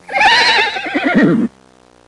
Horse (hyperhorse) Sound Effect
horse-hyperhorse.mp3